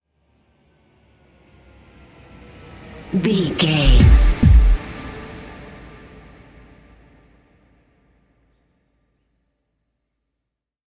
Ionian/Major
D
synthesiser
drum machine
spooky